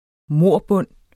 Udtale [ ˈmoˀɐ̯ˌbɔnˀ ]